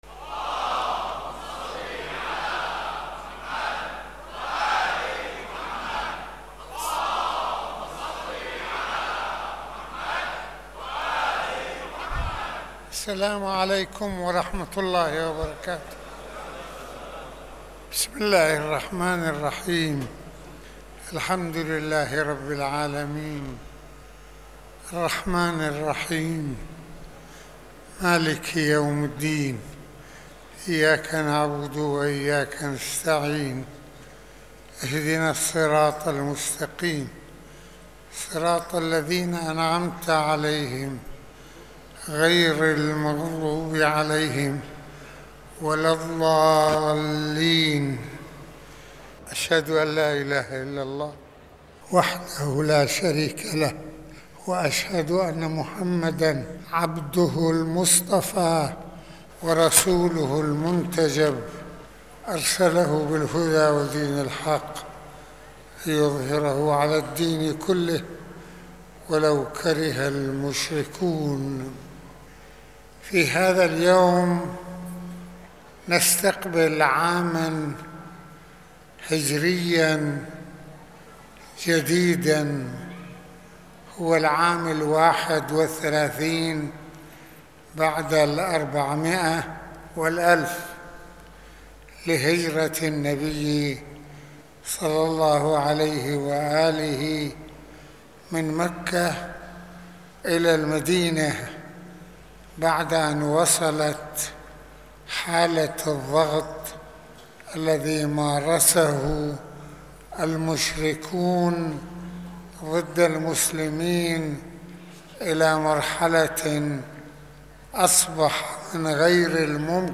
المناسبة : خطبة الجمعة المكان : مسجد الإمامين الحسنين (ع)